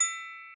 glock_E_5_2.ogg